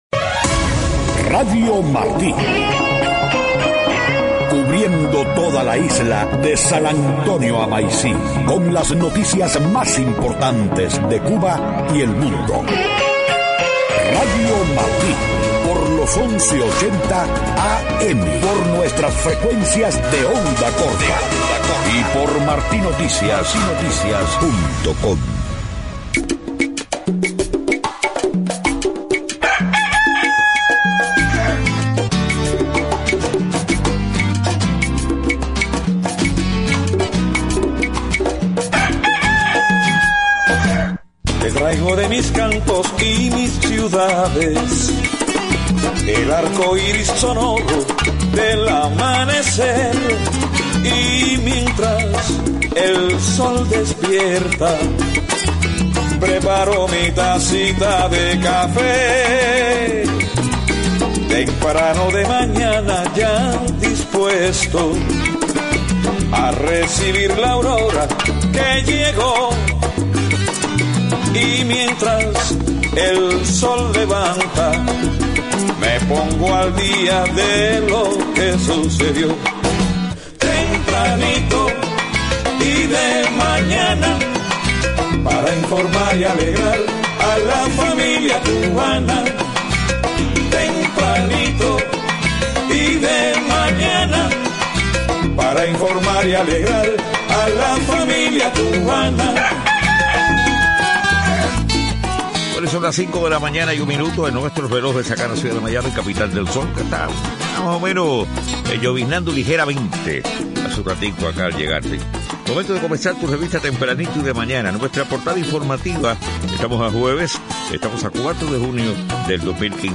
5:00 a.m. Noticias: Aumentaron en mayo los actos de represión por motivos políticos en Cuba, dice informe de grupo opositor.